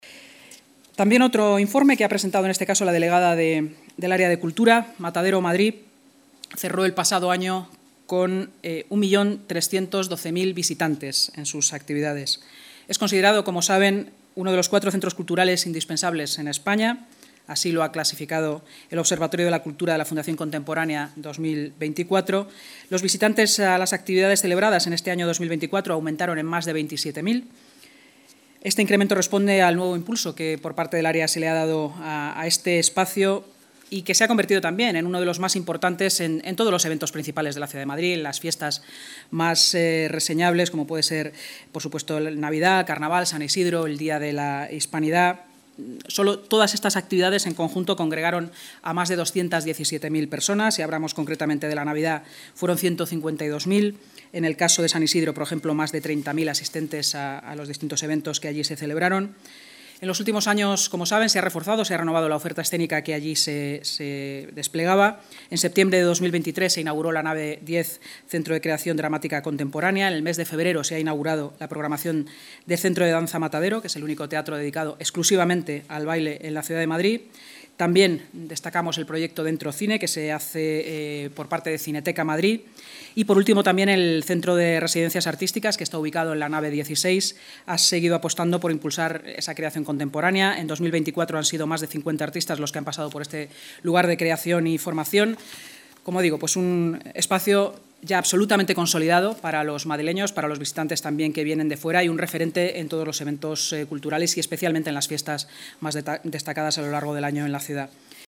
Nueva ventana:Declaraciones de la vicealcaldesa de Madrid, Inma Sanz, durante la rueda de prensa posterior a la Junta de Gobierno, sobre Matadero Madrid como referente cultural: